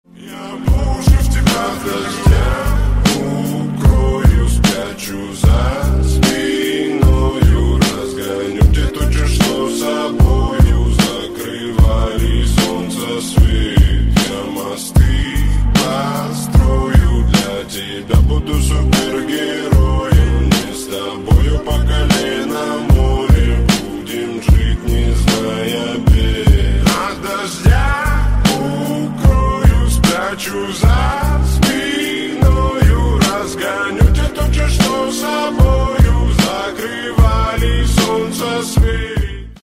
Рингтоны Ремиксы » # Рэп Хип-Хоп Рингтоны